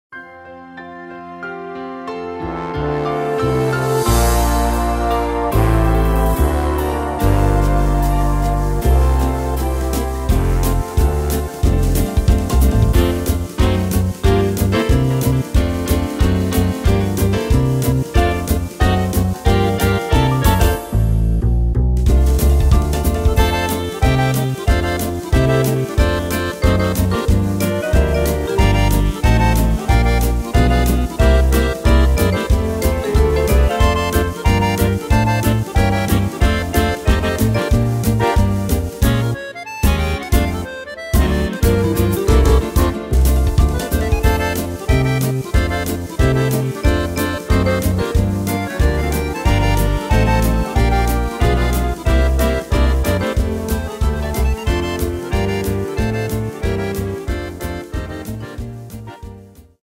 Tempo: 184 / Tonart: F-Dur